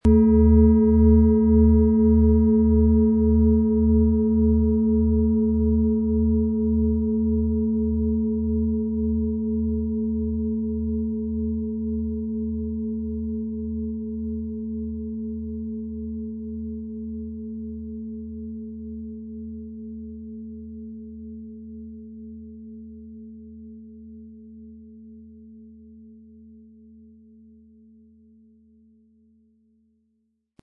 Nach Jahrhunderte alter Tradition von Hand getriebene Planetenklangschale Venus.
Im Audio-Player - Jetzt reinhören hören Sie genau den Original-Klang der angebotenen Schale. Wir haben versucht den Ton so authentisch wie machbar aufzunehmen, damit Sie gut wahrnehmen können, wie die Klangschale klingen wird.
Durch die traditionsreiche Herstellung hat die Schale stattdessen diesen einmaligen Ton und das besondere, bewegende Schwingen der traditionellen Handarbeit.
PlanetentonVenus & Neptun (Höchster Ton)
MaterialBronze